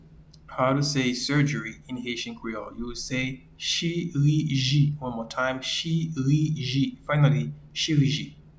Pronunciation and Transcript:
Surgery-in-Haitian-Creole-Chiriji.mp3